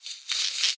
minecraft / sounds / mob / silverfish / say2.ogg